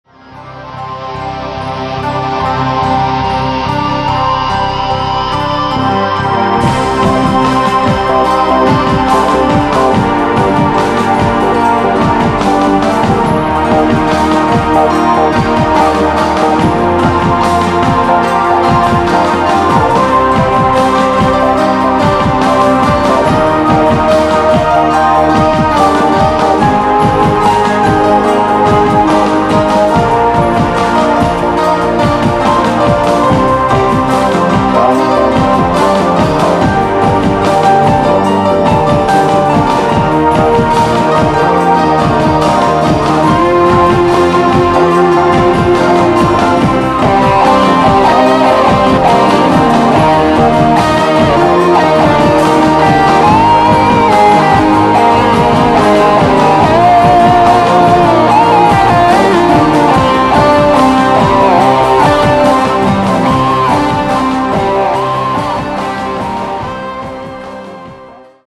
Instrumental Music
Category : New Age Scottish, Celtic Instrumental Music